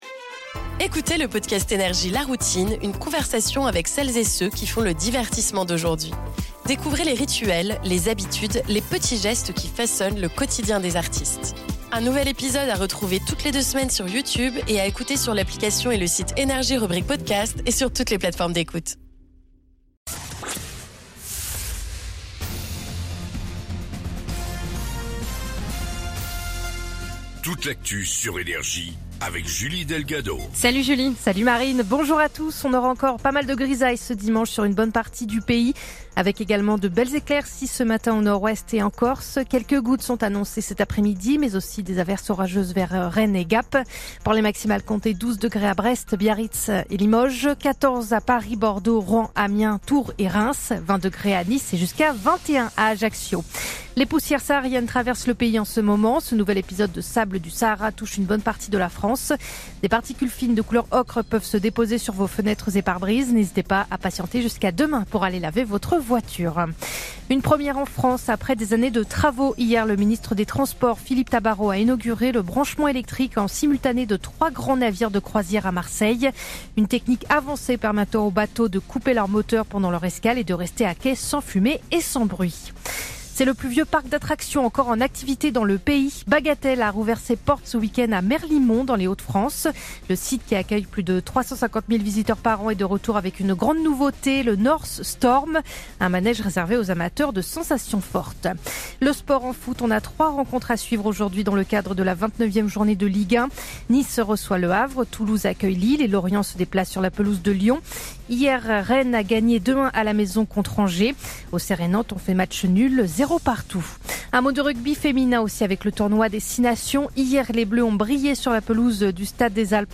Réécoutez vos INFOS, METEO et TRAFIC de NRJ du dimanche 12 avril 2026 à 07h30